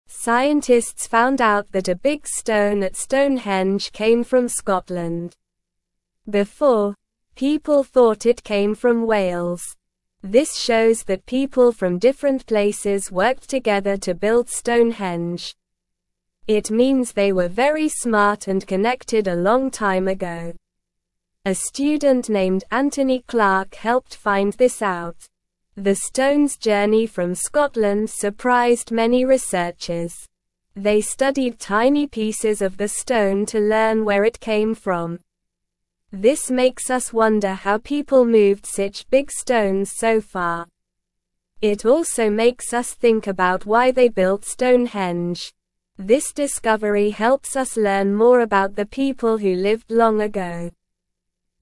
Slow
English-Newsroom-Beginner-SLOW-Reading-Big-Stone-at-Stonehenge-Came-from-Scotland-Not-Wales.mp3